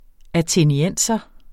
athenienser substantiv, fælleskøn Bøjning -en, -e, -ne Udtale [ ateniˈεnˀsʌ ] Oprindelse fra latin Atheniensis 'fra eller vedr.